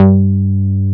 R MOOG G3F.wav